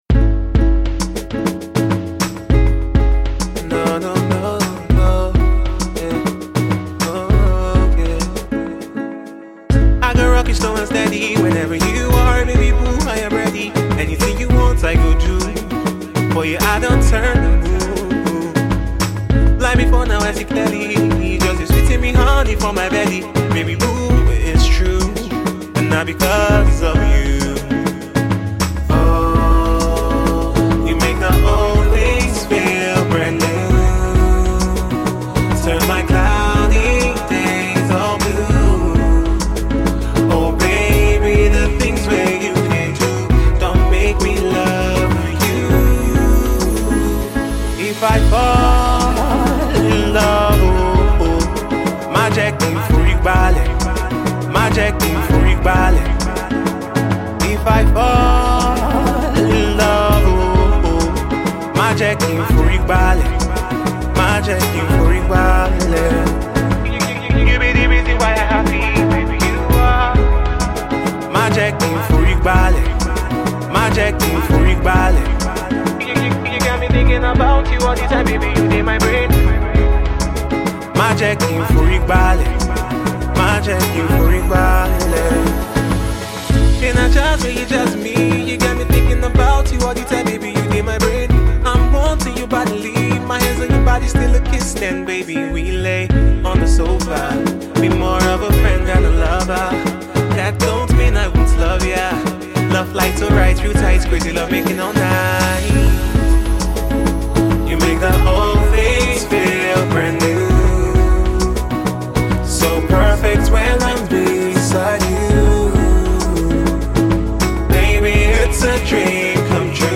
catchy R&B
a touching, sensual love song